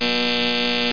BUZZ1.mp3